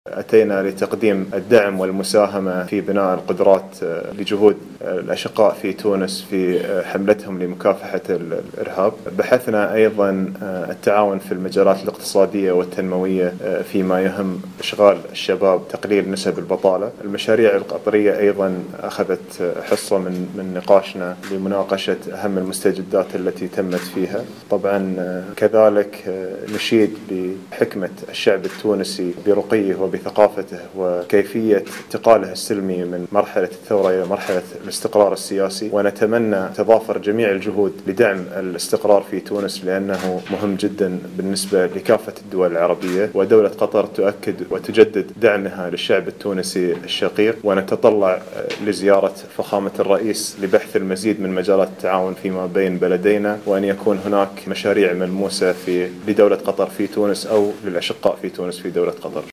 وقال في ندوة صحفية على اثر لقاء جمعه بوزير خارجية تونس خميس الجهيناوي، إنه تم بحث مجالات التعاون الاقتصادي بين البلدين وخاصة على مستوى التشغيل بالإضافة إلى التطرق إلى مستجدات المشاريع القطرية في تونس، بحسب تعبيره.